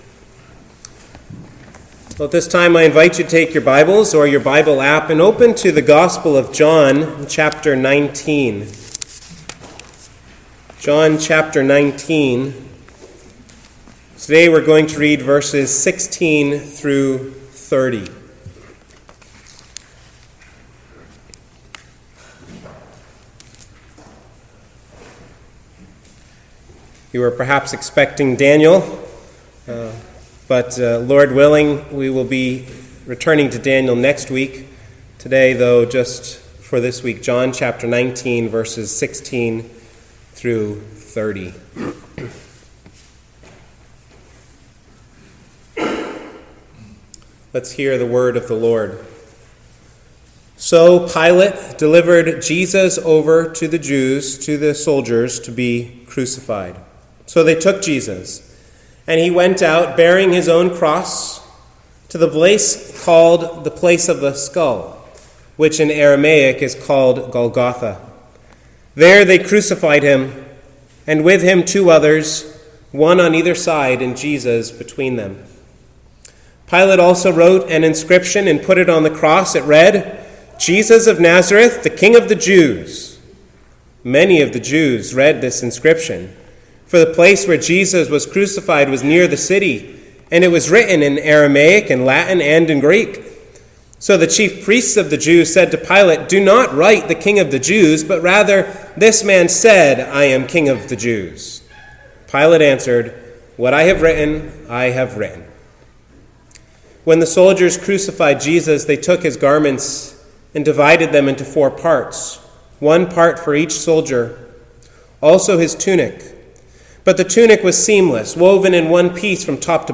The Hidden Hero | SermonAudio Broadcaster is Live View the Live Stream Share this sermon Disabled by adblocker Copy URL Copied!